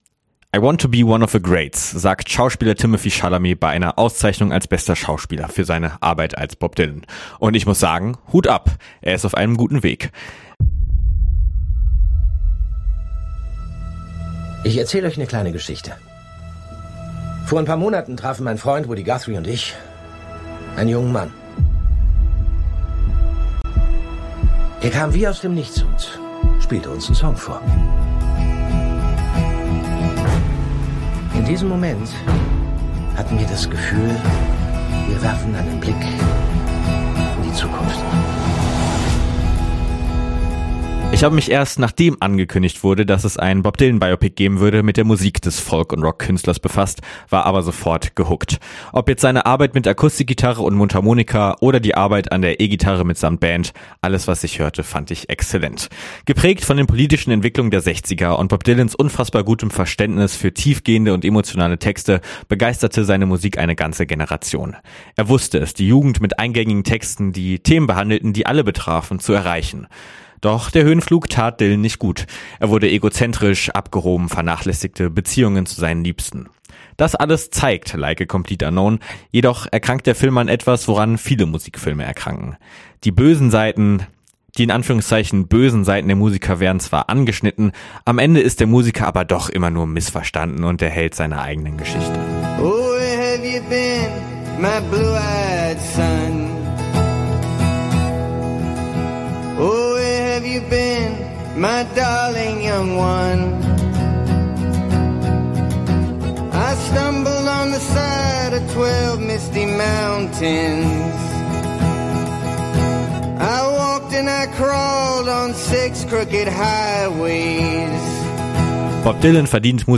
Bob Dylan Kinoreview Like A Complete Unknown